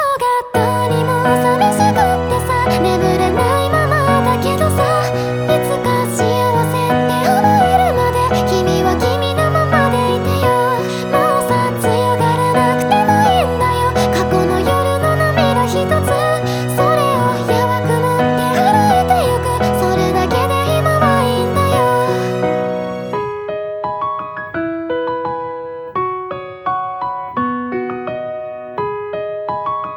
Жанр: Поп / J-pop